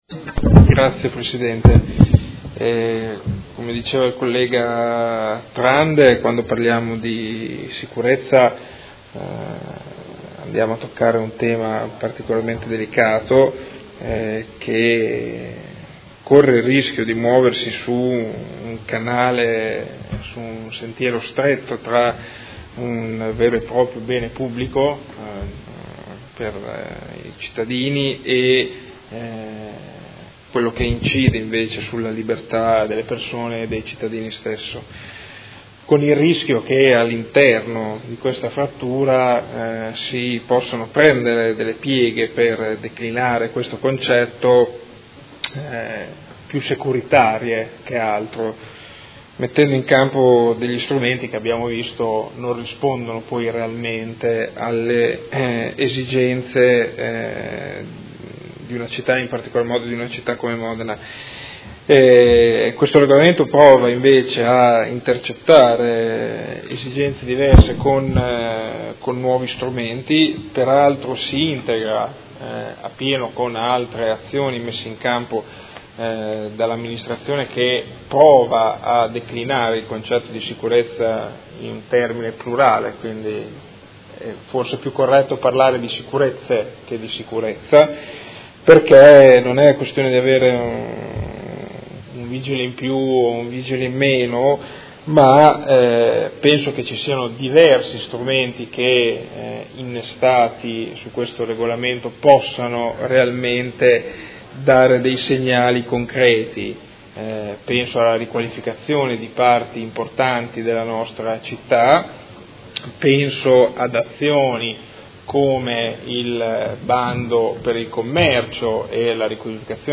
Seduta del 20/07/2017 Dichiarazione di voto. Delibera: Modifiche al Regolamento di Polizia Urbana a seguito della L. 48/2017 – Approvazione e Odg n.11949: Regolamento Polizia Urbana